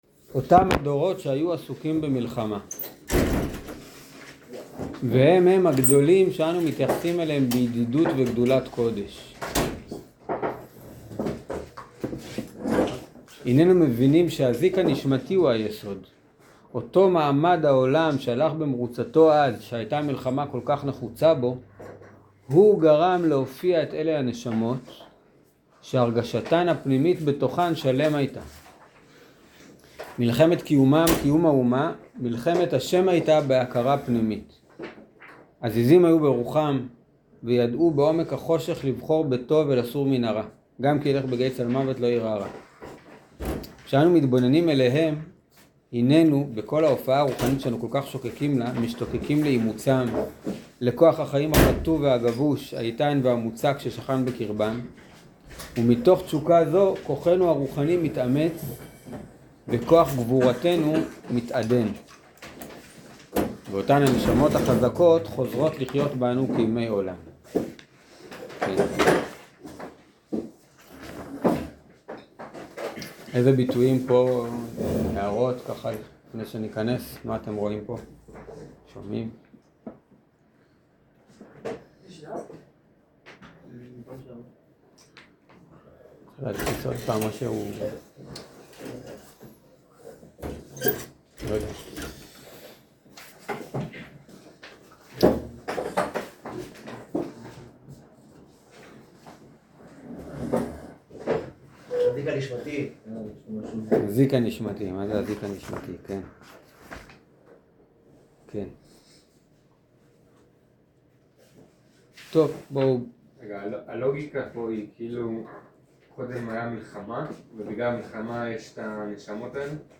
שיעור פסקאות ב'